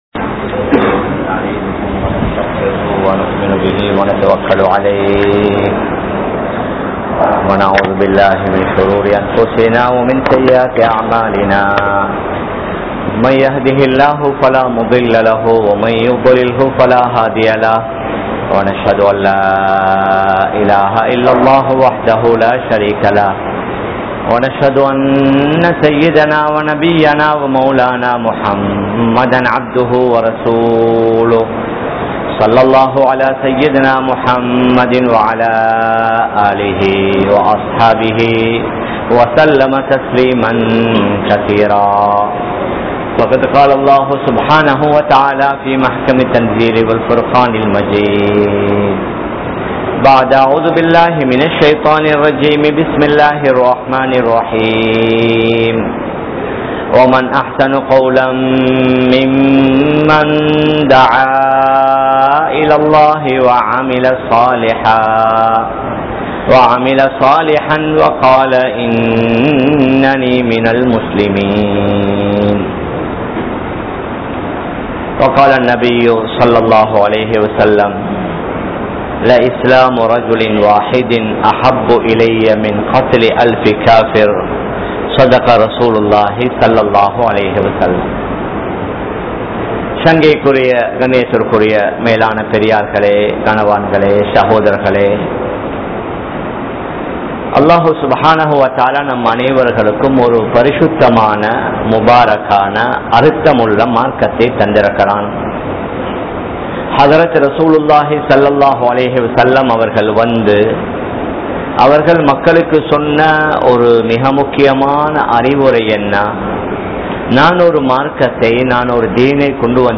Ungalukkaaha Islathai Maattraatheerhal (உங்களுக்காக இஸ்லாத்தை மாற்றாதீர்கள்) | Audio Bayans | All Ceylon Muslim Youth Community | Addalaichenai
Kollupitty Jumua Masjith